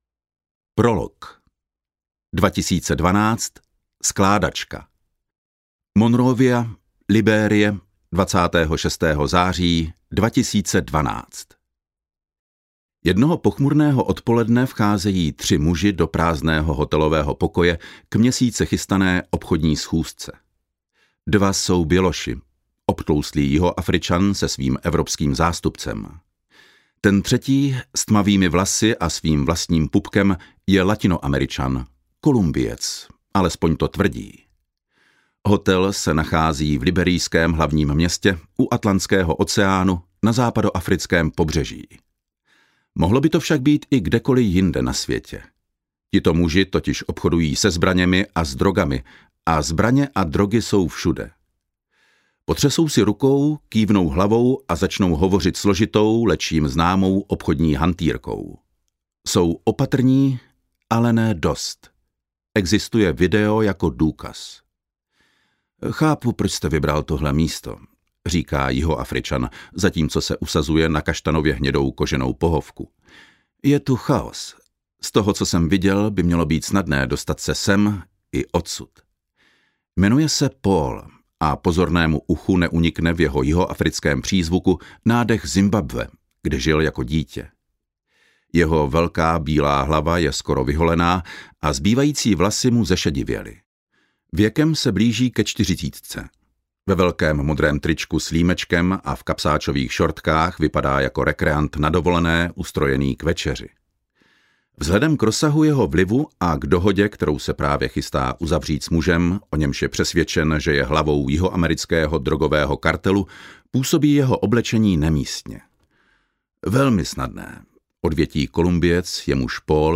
Audiokniha Mastermind